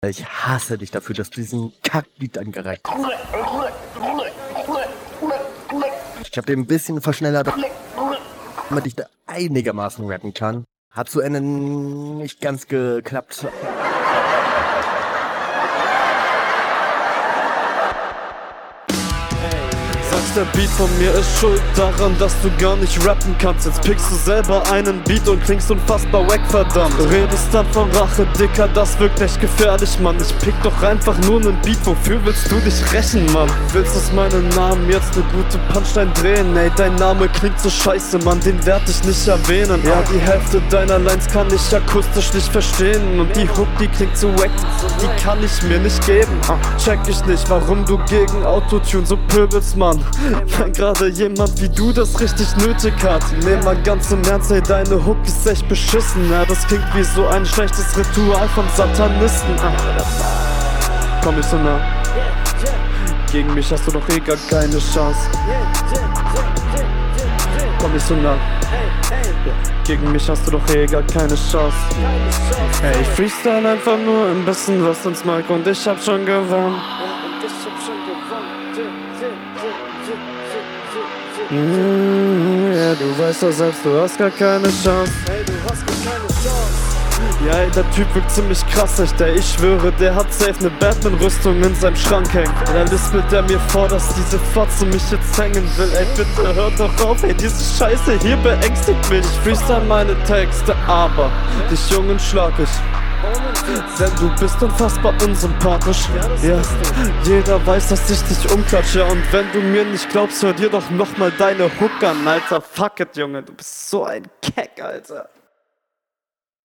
Finde deine Cleane-Stimme deutlich besser als den Autotune-kram.